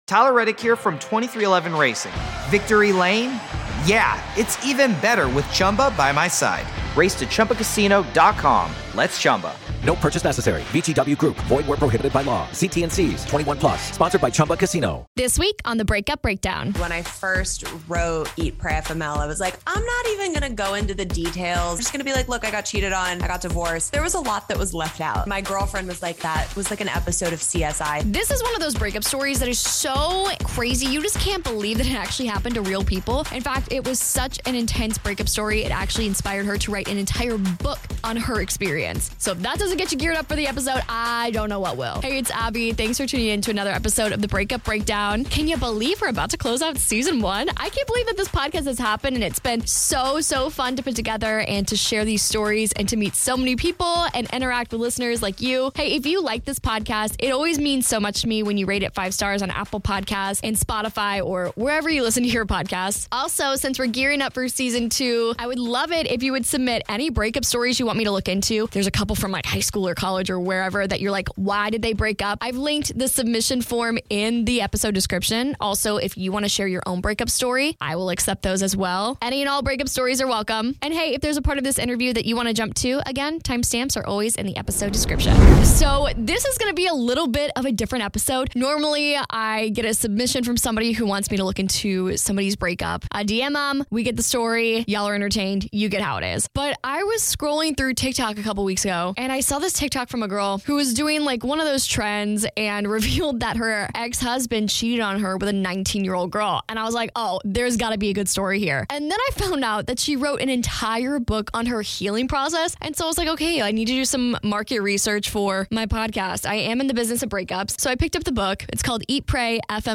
Interview Begins